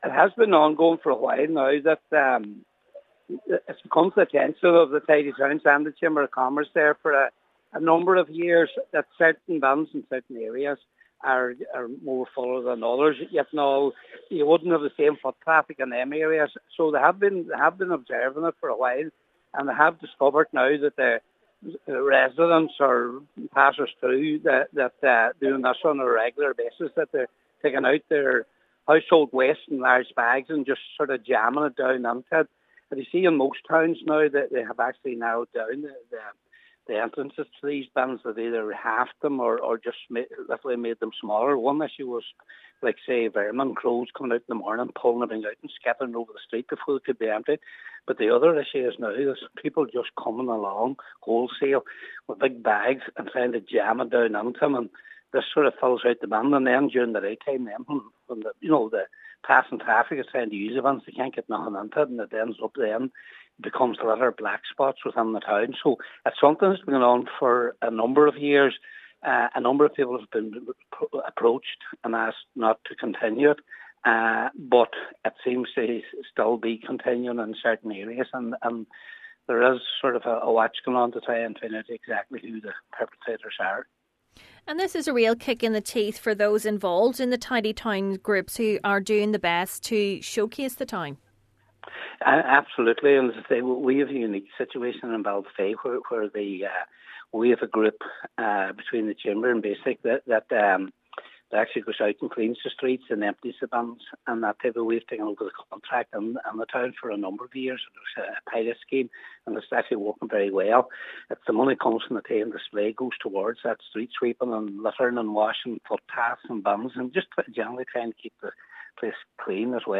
Cathaoirleach of the Lifford Stranorlar Municipal District, Councillor Patrick McGowan says efforts have been ongoing for some time now to ensure the bins are used for their intended use: